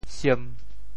蔘 部首拼音 部首 艹 总笔划 14 部外笔划 11 普通话 shēn 潮州发音 潮州 siem1 白 潮阳 sim1 白 澄海 siang1 白 揭阳 sim1 白 饶平 som1 白 汕头 siam1 白 中文解释 siem1、澄海siang1、饒平som1、潮陽sim1、汕頭siam1（對應普通話shēn） 人蔘，多年生草本植物。